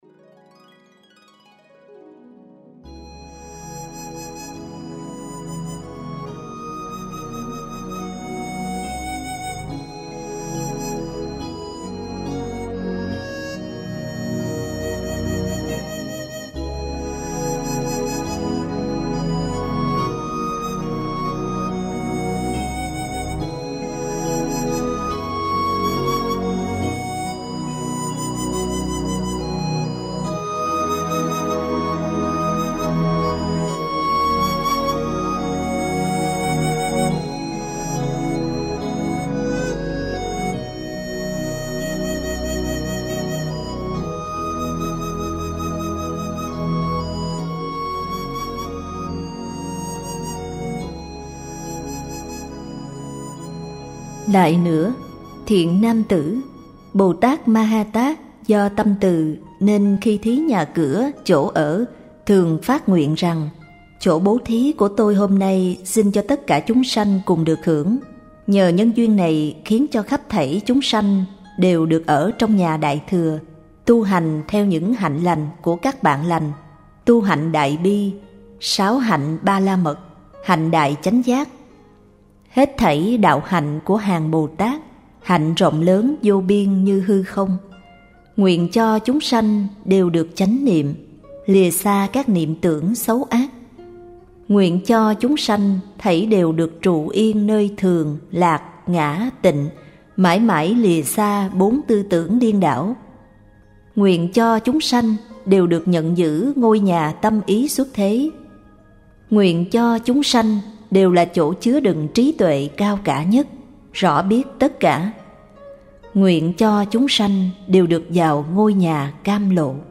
39_Cảnh Giới Bất Nhị (bài giảng